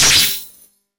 Laser Blast
A sharp, punchy laser blast with electronic zap and quick decay
laser-blast.mp3